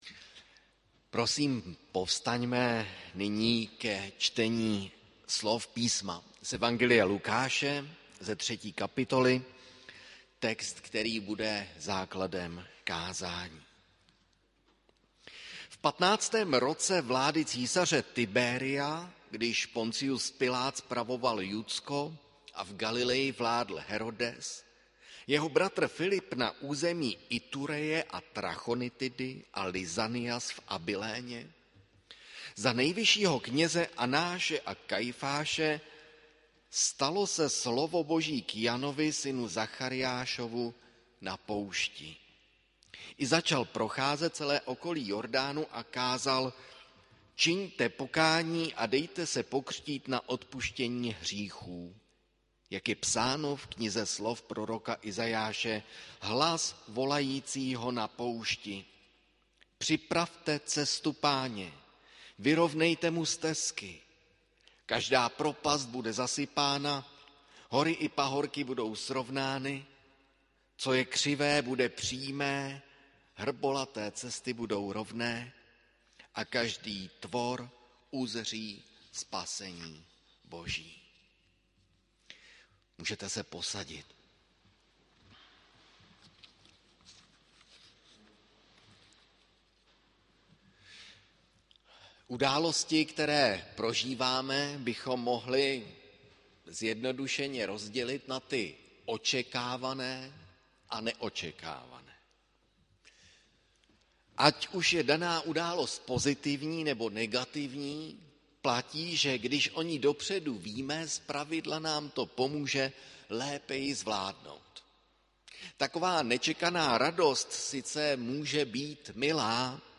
Hudební nešpory 5. prosince 2021 AD
ADVENT v hudbě českého a německého baroka. Zpíval pěvecký sbor Resonance
varhany